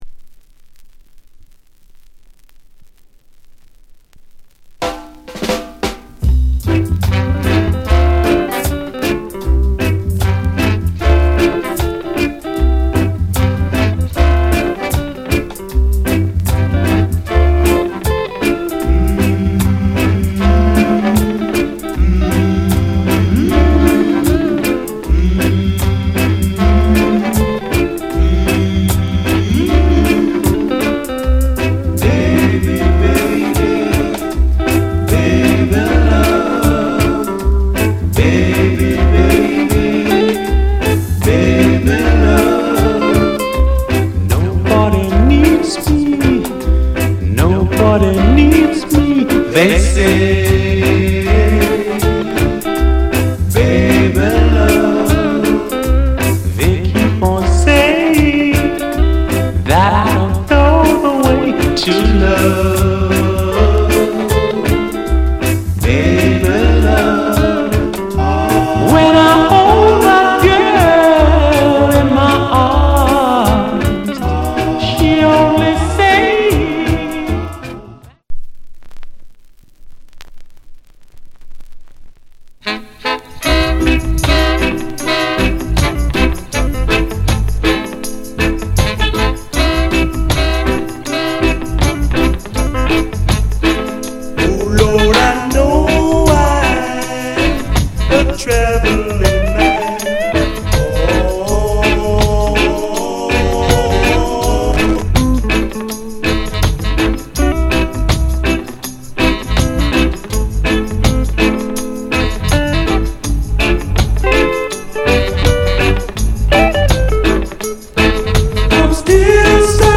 Genre Rock Steady / Male Vocal Male DJ